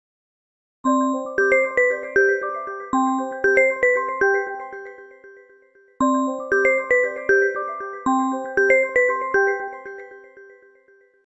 звонкие